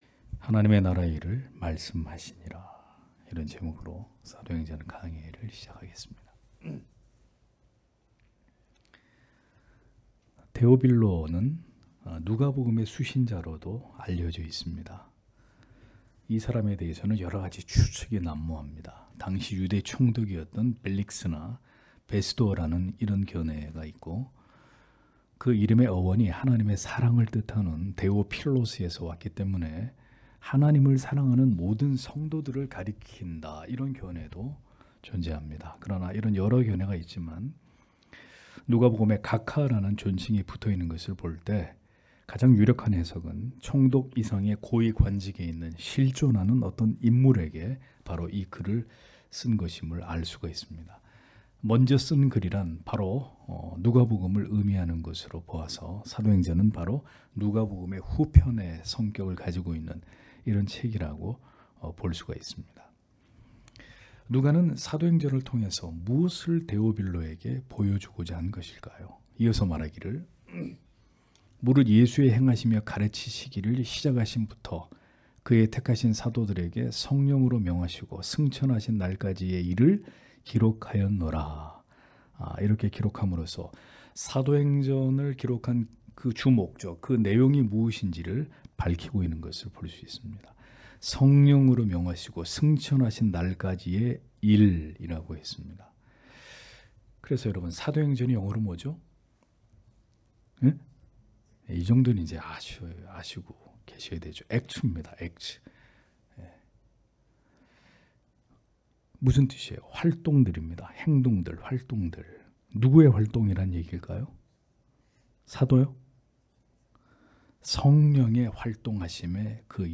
금요기도회 - [사도행전 강해 01] '하나님 나라의 일을 말씀하시니라' (행 1장 1- 3절)